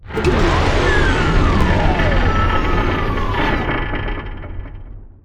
rocket.wav